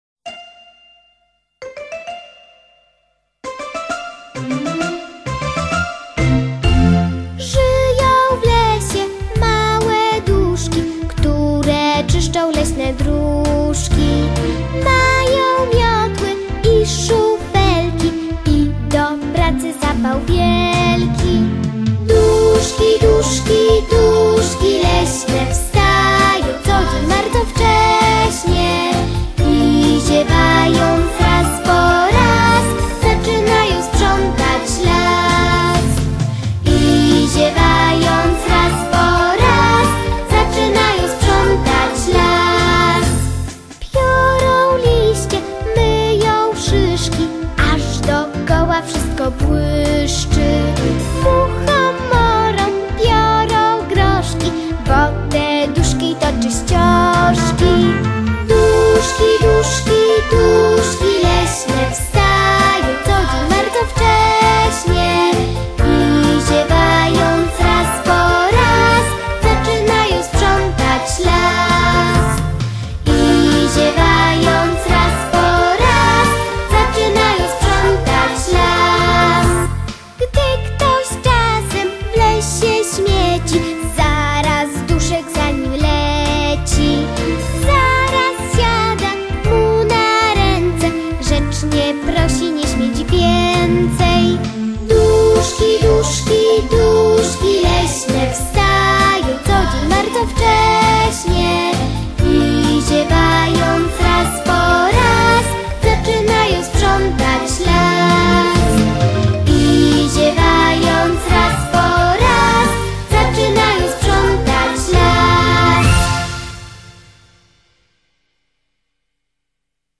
Małe sprzątanie wielkiego świata „Leśne duszki” - zabawy wokalno- ruchowe z piosenką.